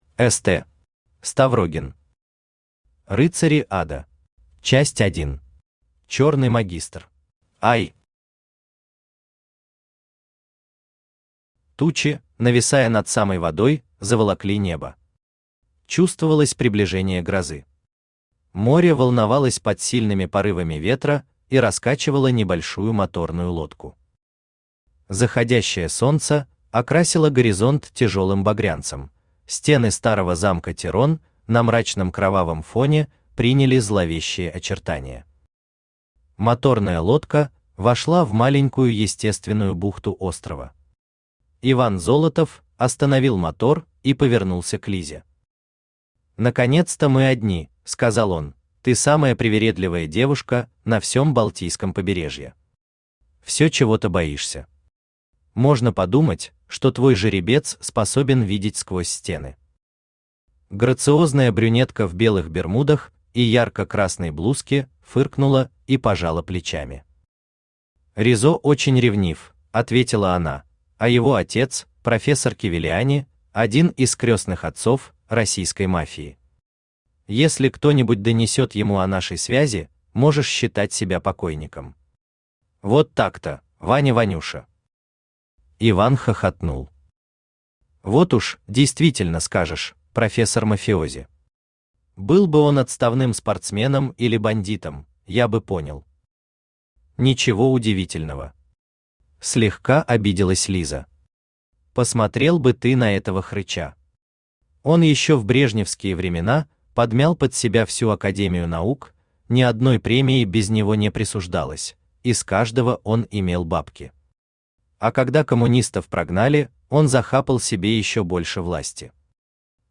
Аудиокнига Рыцари ада | Библиотека аудиокниг
Ставрогин Читает аудиокнигу Авточтец ЛитРес.